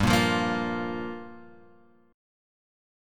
Gm7 chord {3 5 3 3 3 3} chord